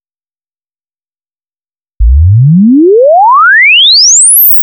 Acoustics Test / Pallas Plus Chirp Test V2